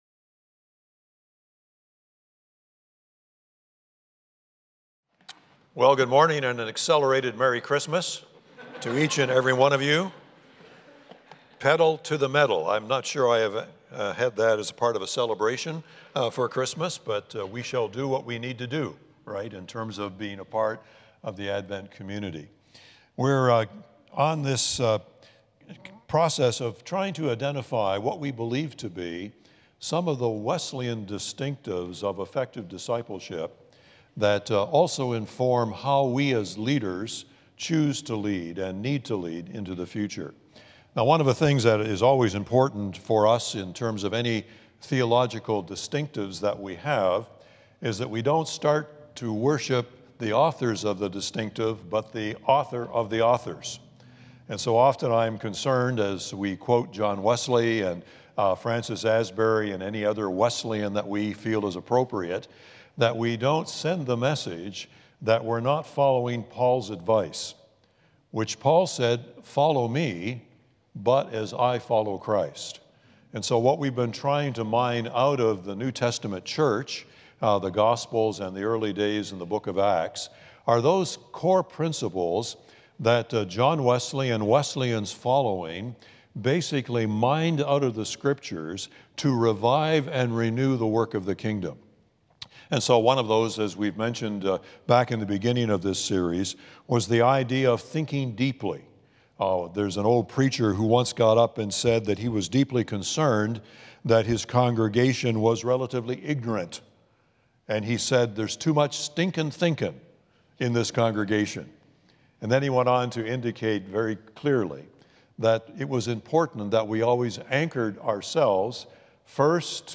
The following service took place on Tuesday, December 3, 2024.